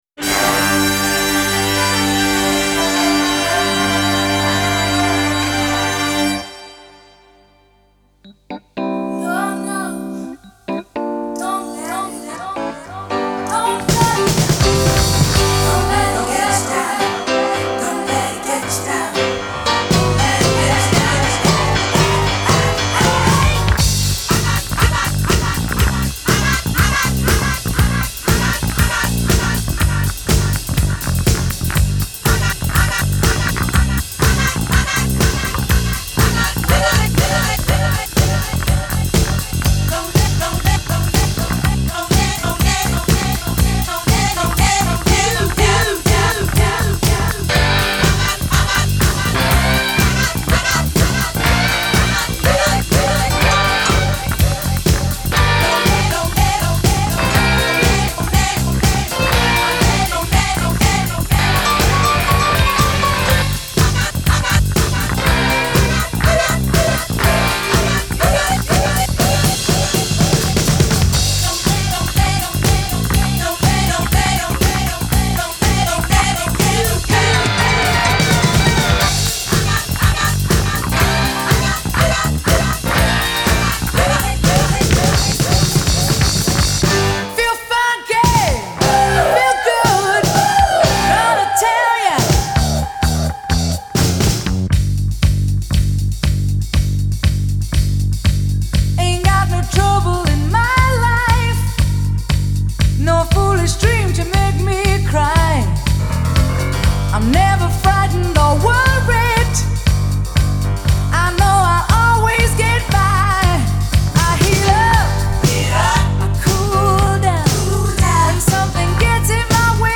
Genre : Soul